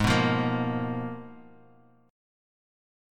G#m6add9 chord